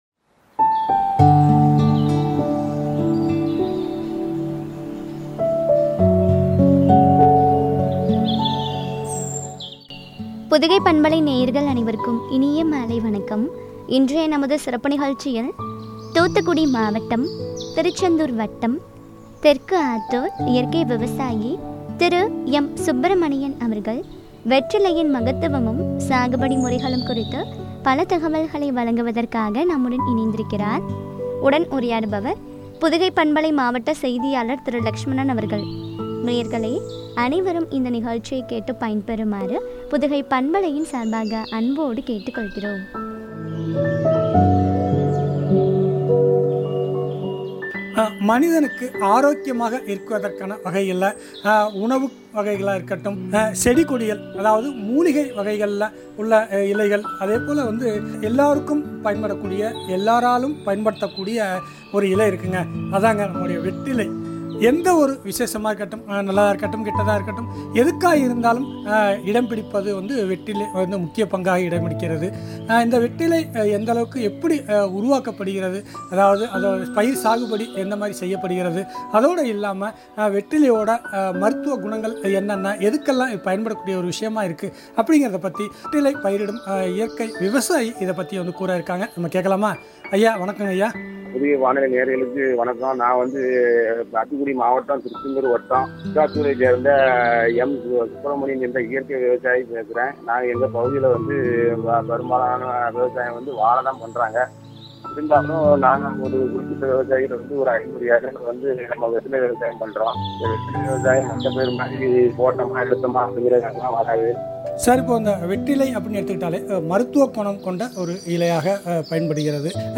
வெற்றிலையின் மகத்துவமும், சாகுபடி முறைகளும் பற்றிய உரையாடல்.